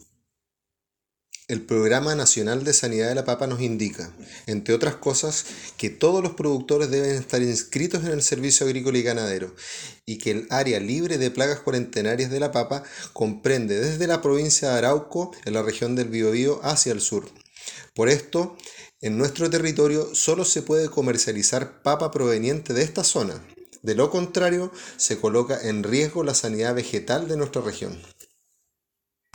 Cuna-DR-s-SAG.mp3